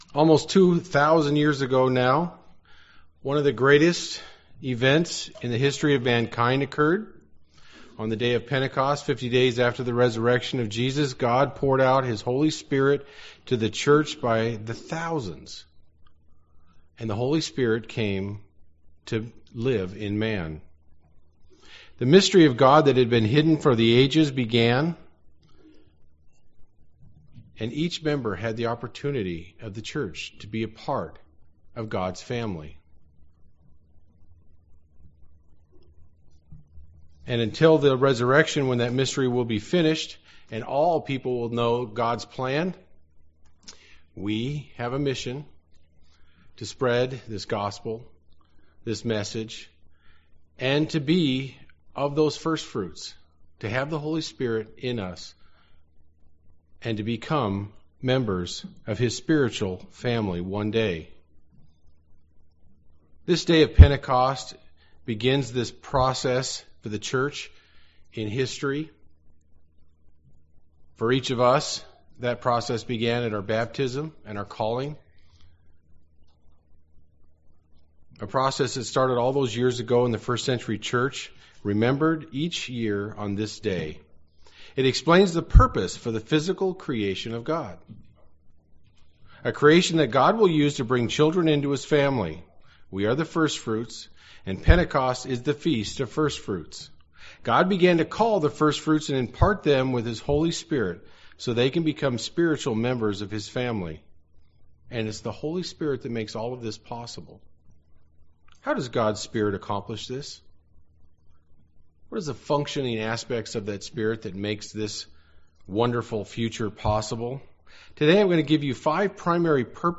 The Title of this Sermon is taken from John 4:24: